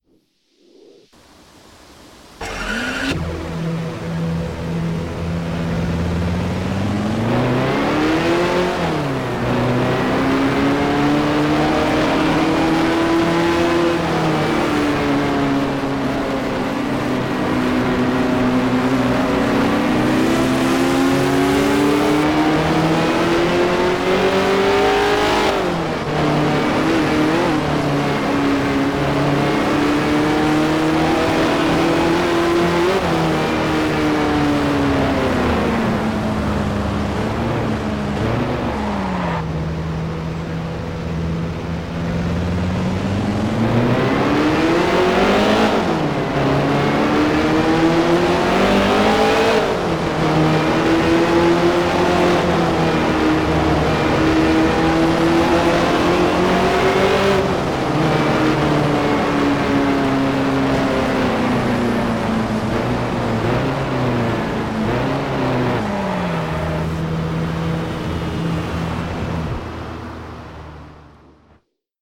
- Pagani Zonda F